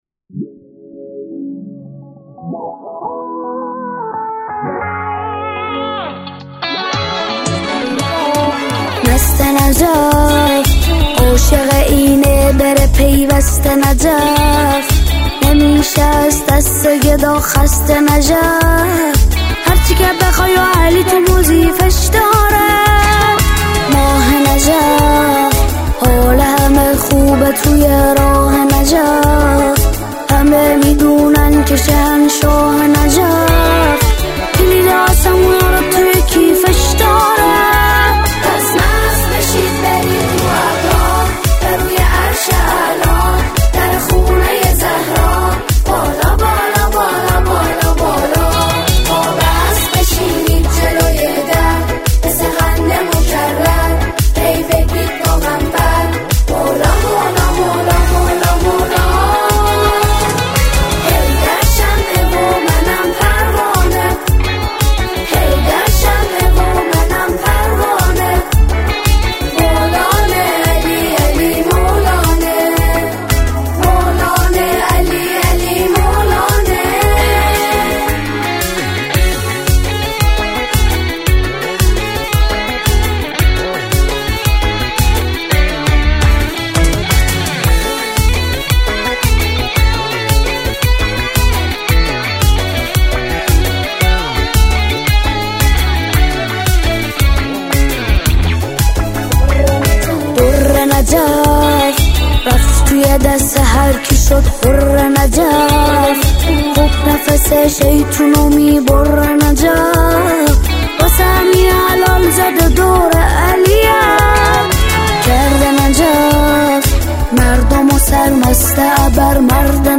به صورت جمع خوانی به مرحله اجرا درآمده است.